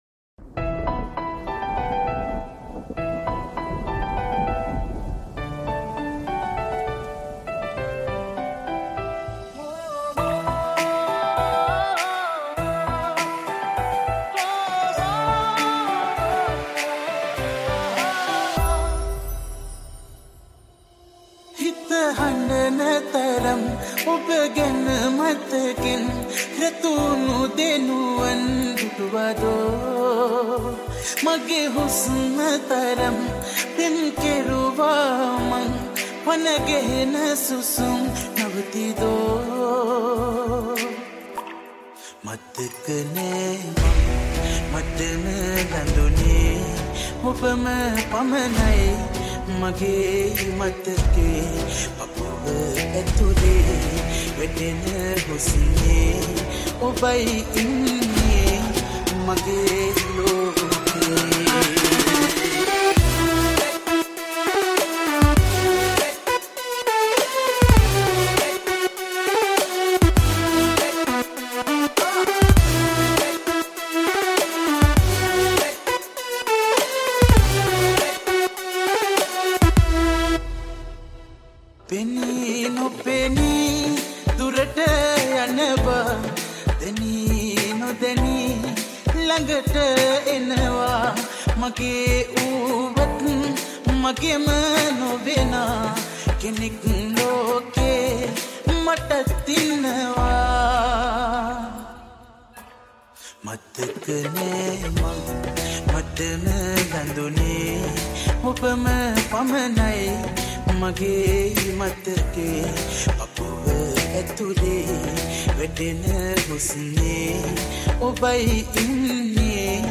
Sinhala Dj Remix